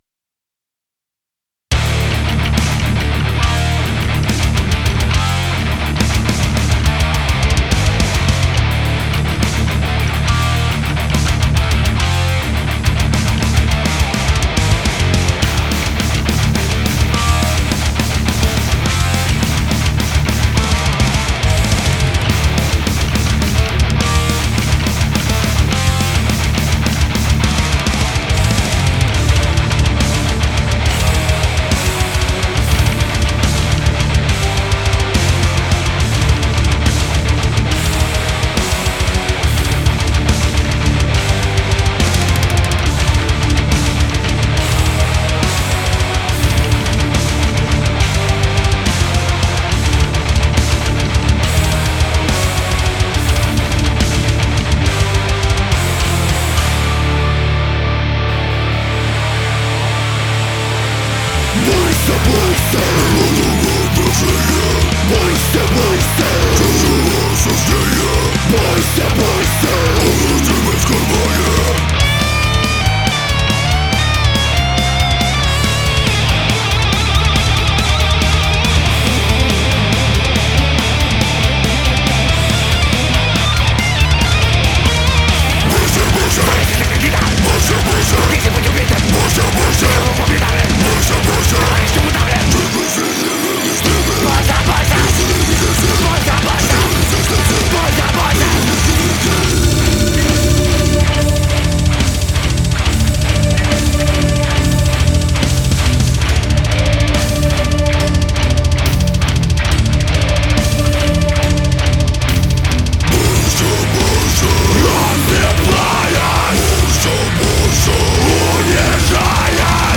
Death/Grind/Experimental
Гитары,бас - записано в линию на карту Lynx two и в DBX 376. Мастеринг не делал.Так зажал лимитером на 2 дб